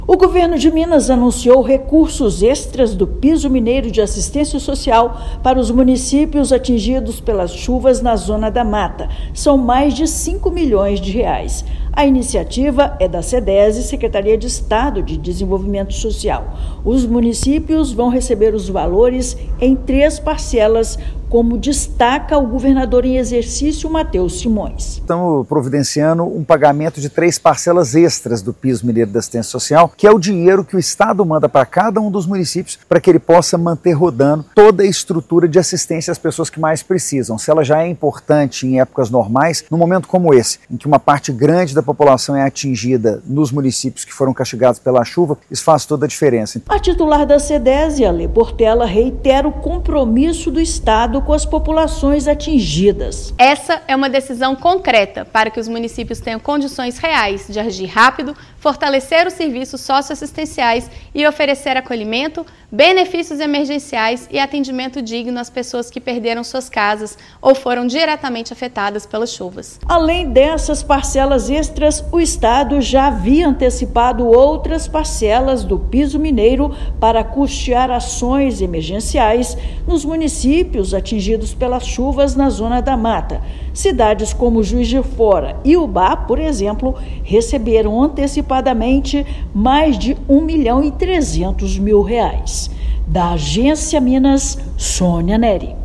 Medida amplia capacidade de resposta rápida das prefeituras e garante atendimento às famílias atingidas pela chuva. Ouça matéria de rádio.